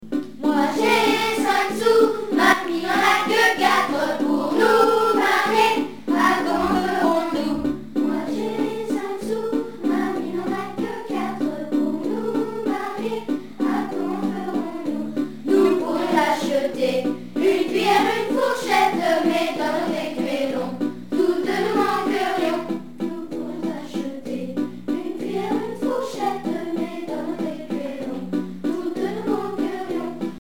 Bourrée